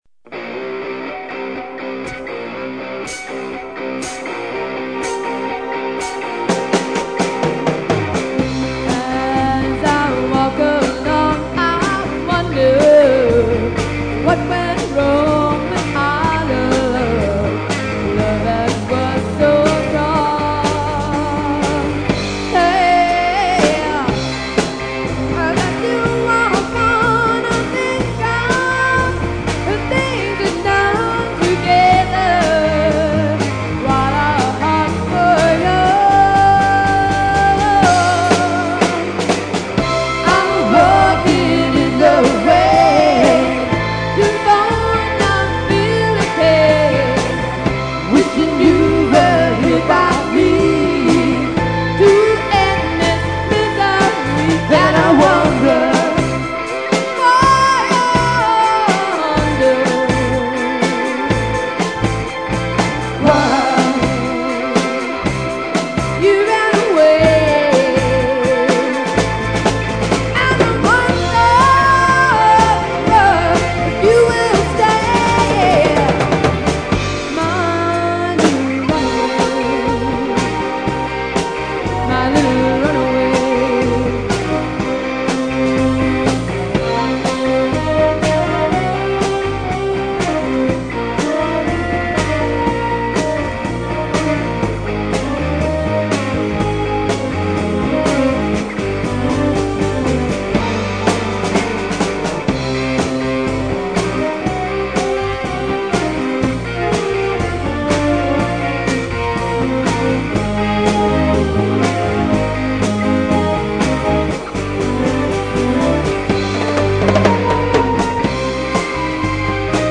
ROCK 'N ROLL
Live At L. C. Saloon 1/16/83 + Bonus Tracks